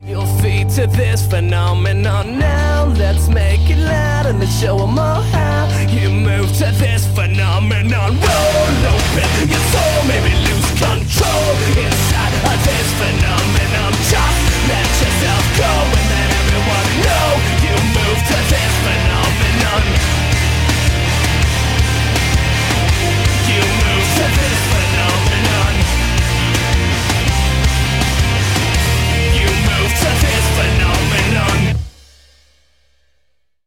• Качество: 320, Stereo
мужской вокал
Alternative Rock
christian rock
христианский рок